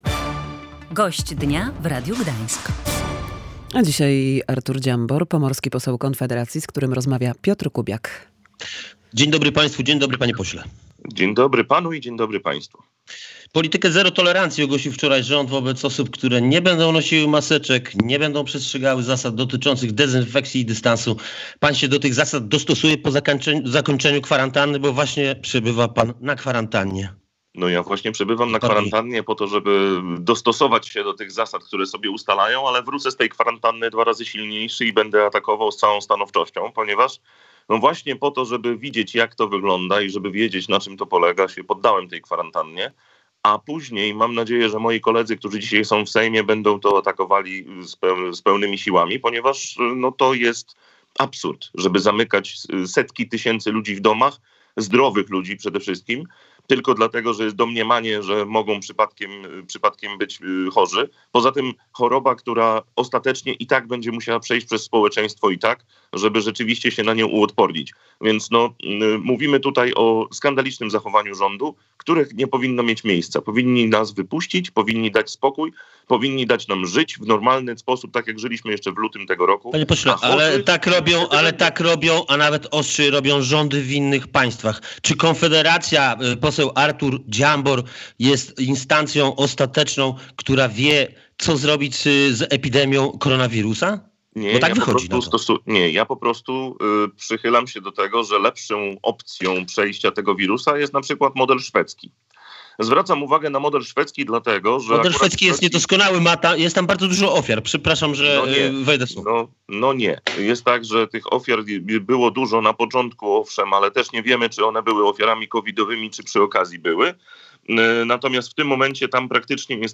Obowiązek noszenia maseczek to polityczny szantaż - mówił w Radiu Gdańsk poseł Konfederacji Artur Dziambor. Jego zdaniem nie ma epidemii.